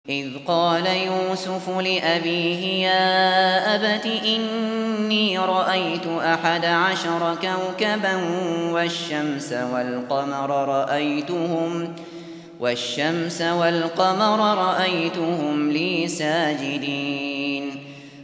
Yusuf-4, Quran Recitation by Abu Bakr al Shatri